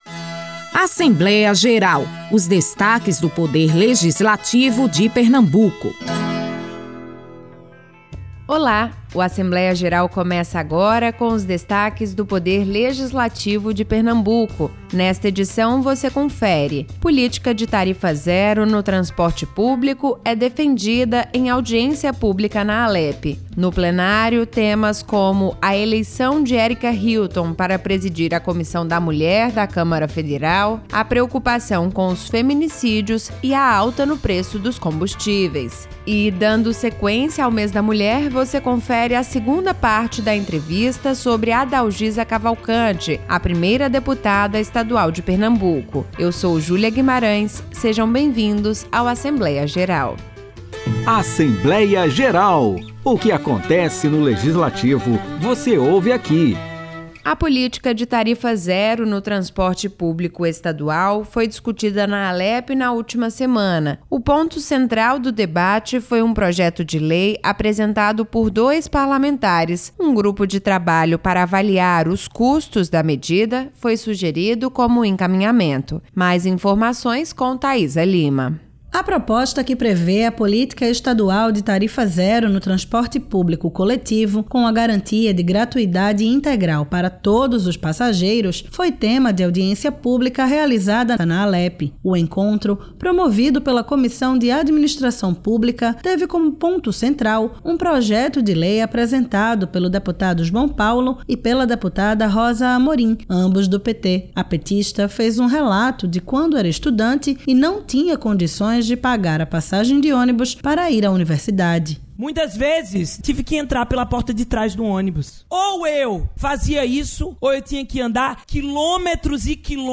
No Assembleia Geral desta semana, os detalhes da audiência pública que debateu a tarifa zero no transporte público. Tem ainda os destaques do Plenário e a segunda parte da entrevista sobre Adalgisa Cavalcanti, primeira deputada estadual de Pernambuco. O programa é uma produção da Rádio Alepe e está disponível em plataformas de áudio como Deezer e Spotify.